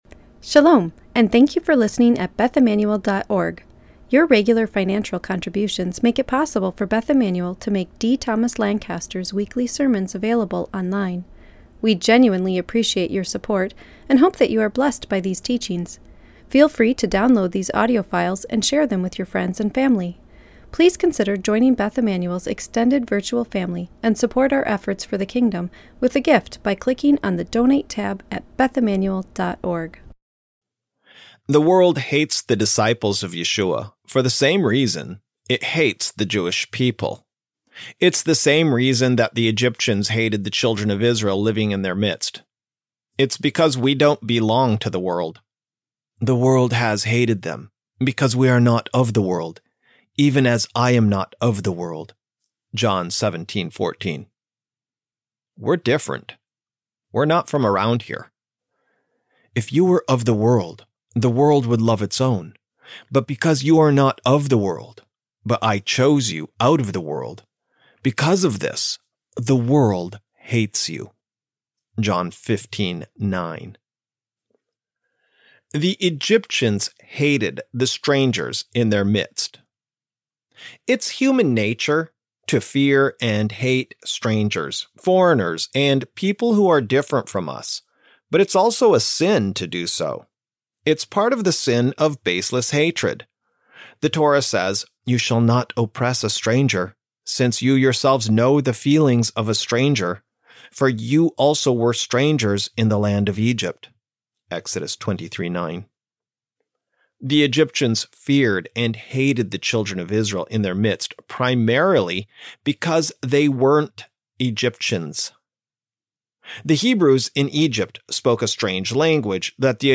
This feed offers Messianic Jewish audio teachings, including Torah commentaries and concepts in Messianic Judaism.